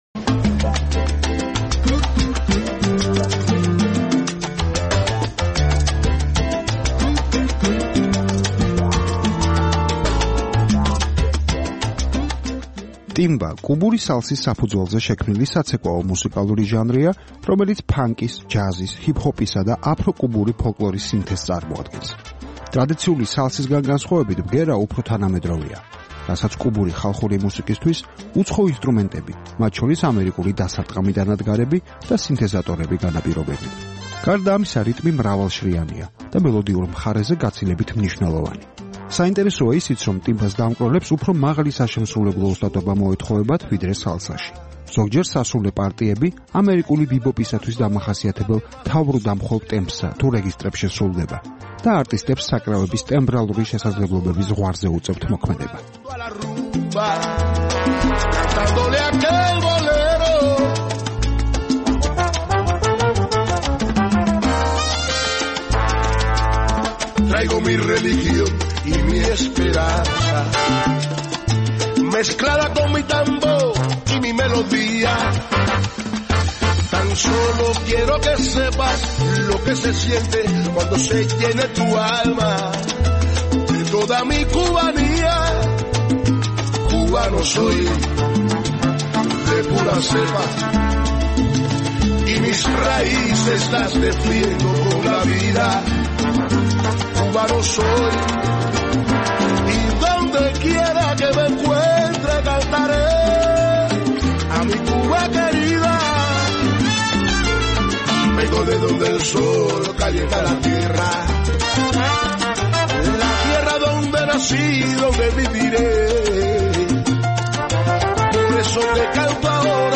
ტიმბა კუბური სალსის საფუძველზე შექმნილი საცეკვაო მუსიკალური ჟანრია, რომელიც ფანკის, ჯაზის, ჰიპ-ჰოპისა და აფრო-კუბური ფოლკლორის სინთეზს წარმოადგენს. ტრადიციული სალსისგან განსხვავებით, ბგერა უფრო ნოვატორული და თანამედროვეა, რასაც კუბური ხალხური მუსიკისათვის უცხო ინსტრუმენტები - მათ შორის, ამერიკული დასარტყამი დანადგარები და სინთეზატორები -განაპირობებს. გარდა ამისა, რიტმი მრავალშრიანია და მელოდიურ მხარეზე გაცილებით მნიშვნელოვანი.
საინტერესოა ისიც, რომ ტიმბას დამკვრელებს უფრო მაღალი საშემსრულებლო ოსტატობა მოეთხოვებათ, ვიდრე სალსაში. ზოგჯერ სასულე პარტიები ამერიკული ბიბოპისათვის დამახასიათებელ თავბრუდამხვევ ტემპსა თუ რეგისტრებში სრულდება და არტისტებს საკრავების ტემბრალური შესაძლებლობების ზღვარზე უწევთ მოქმედება.